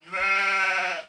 Schaf1_ORIG-1503.wav